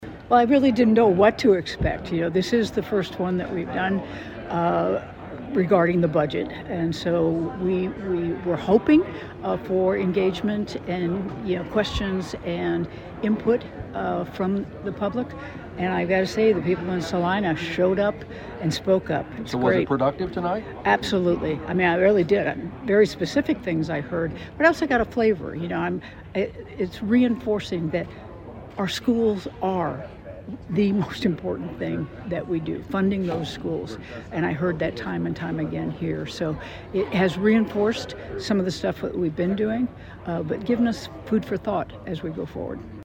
Following the event the Governor told KSAL News the event exceeded her expectations.